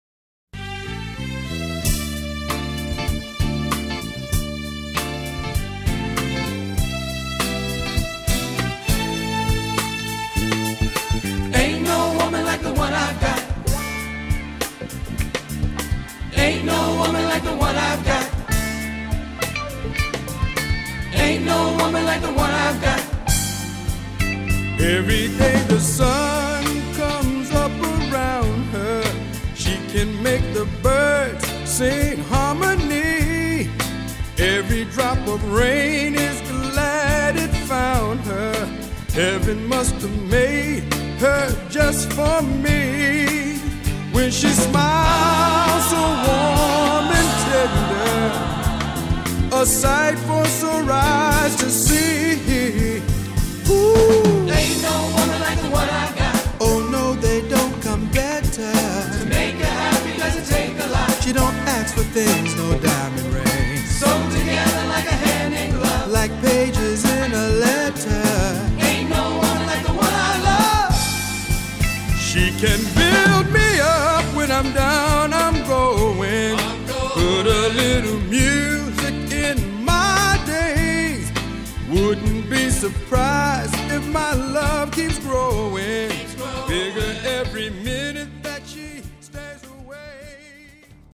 classic soul and funk music from the 60s and 70s